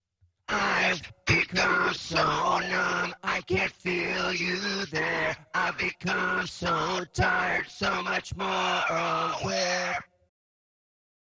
The band I was in back then used VOCODERS.